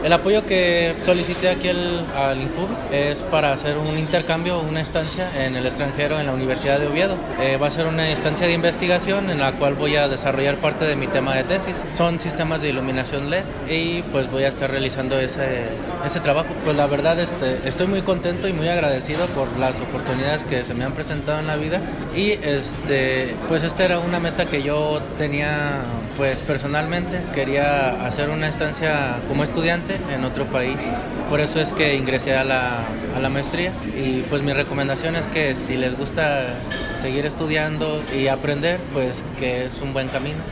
AudioBoletines
Estudiante del ITESI